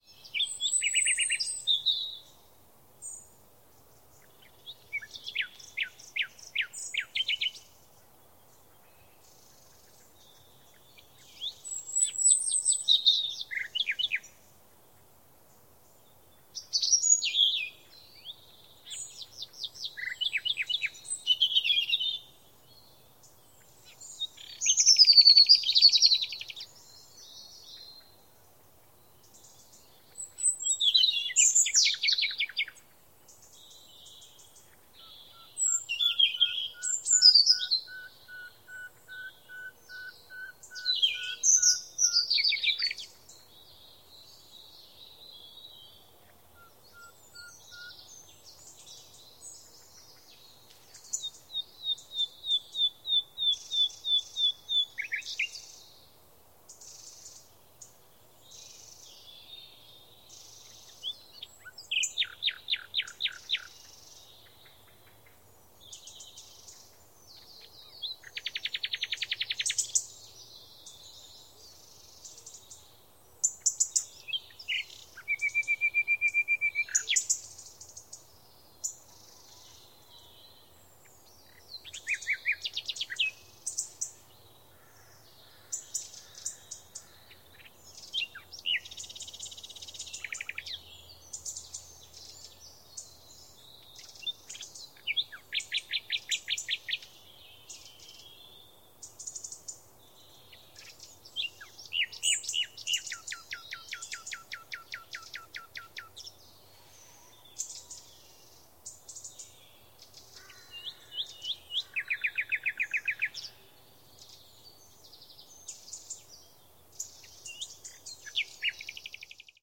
Forest_day.ogg